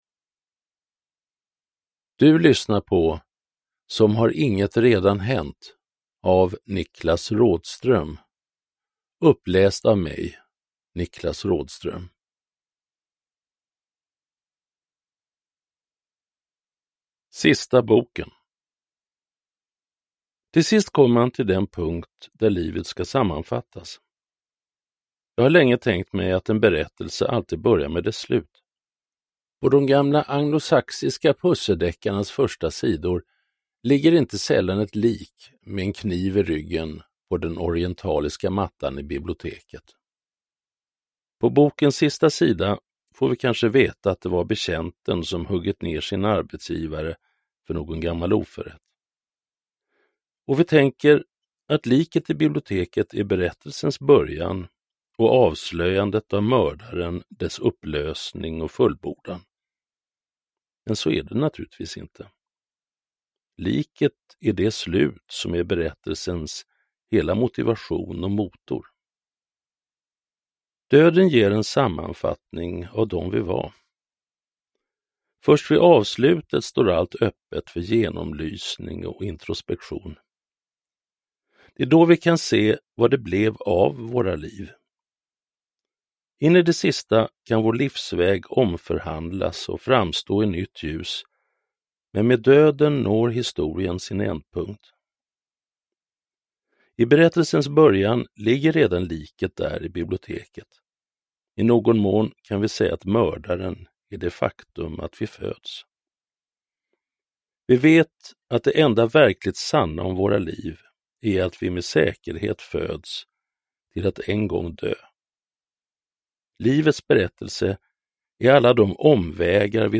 Som har inget redan hänt – Ljudbok – Laddas ner
Uppläsare: Niklas Rådström